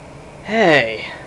Hey Sound Effect
Download a high-quality hey sound effect.